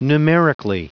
Prononciation du mot numerically en anglais (fichier audio)
Vous êtes ici : Cours d'anglais > Outils | Audio/Vidéo > Lire un mot à haute voix > Lire le mot numerically
numerically.wav